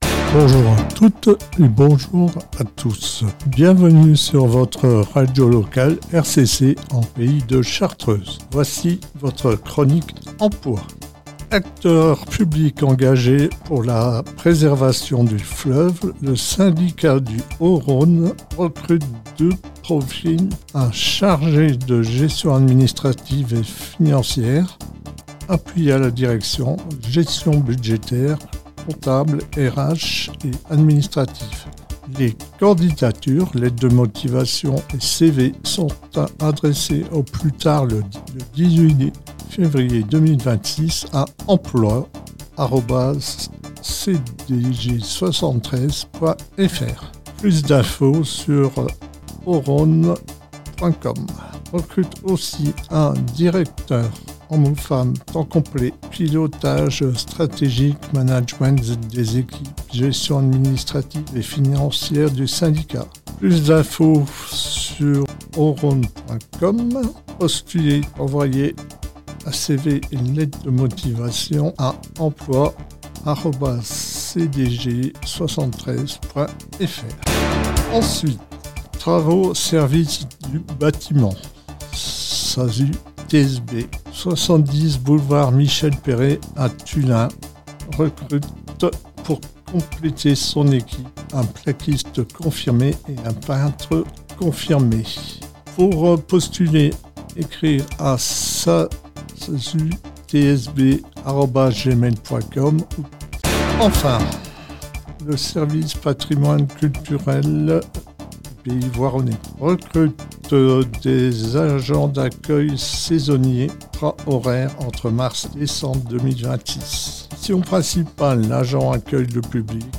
Chaque semaine, le mercredi, RCC diffuse la chronique emploi dans la matinale « Bonjour la chartreuse » de 6h à 10h et dans le Chartreuse infos 16h-18h vers 40′ de chaque heure.